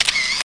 clacks.mp3